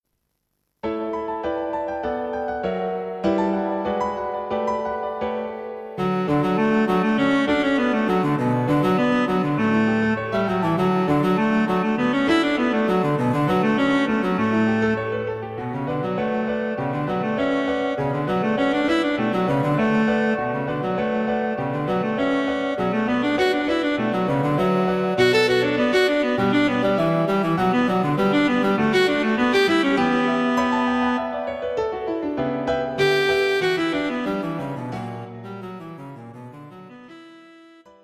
Cello and Piano Ready for take-off!